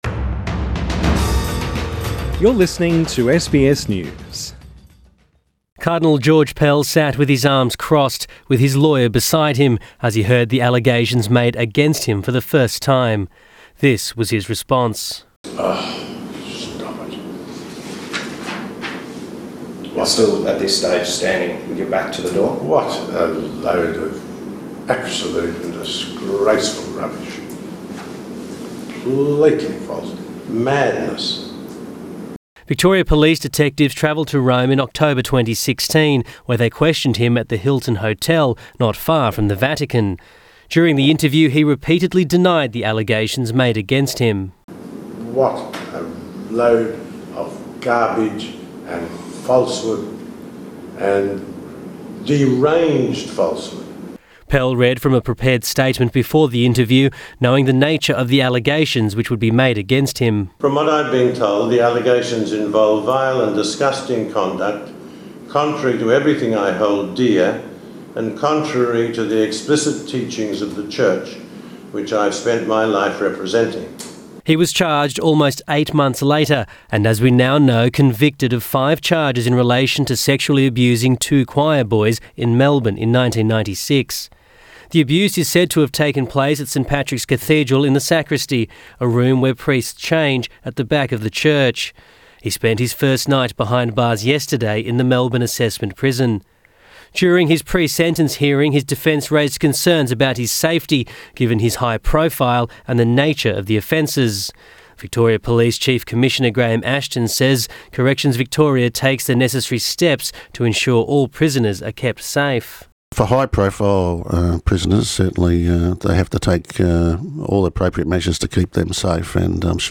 Police interview with Pell revealed